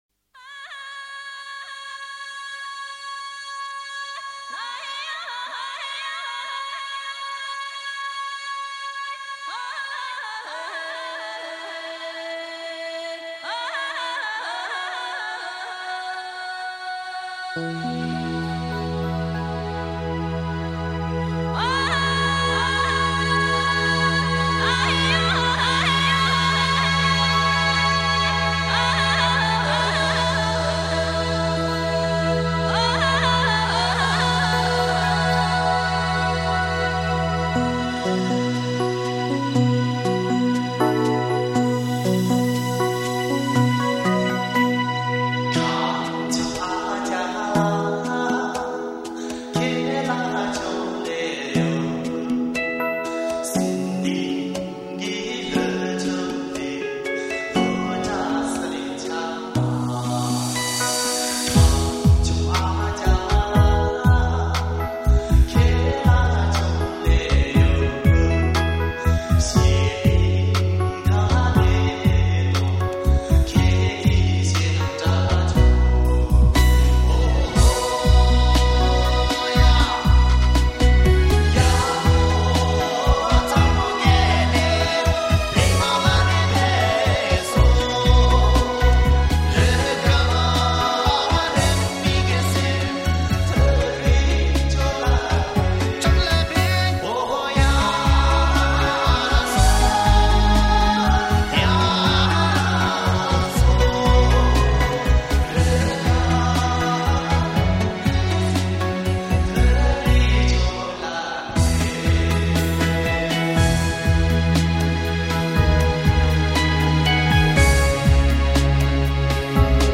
他们的眼睛看不见世界  但他们却以来自天籁一样的歌声